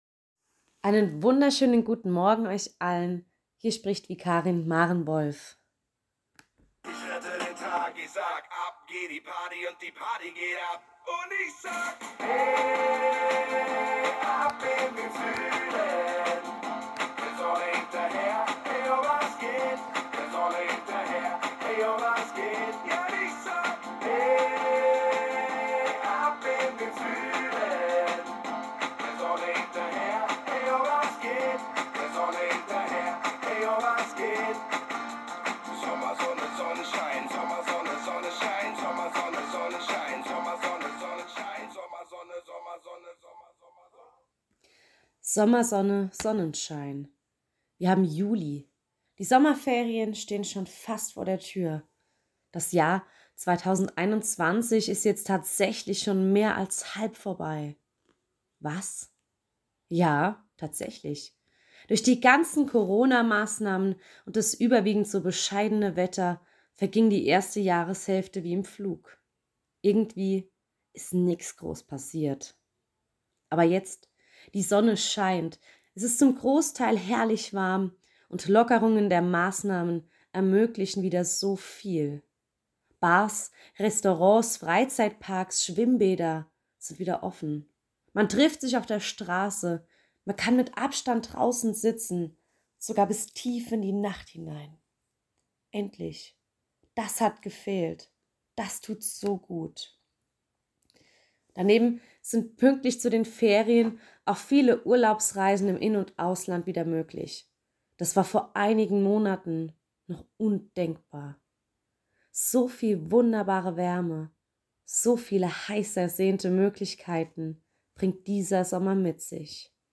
Neue Audioandachten online